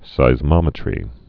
(sīz-mŏmĭ-trē)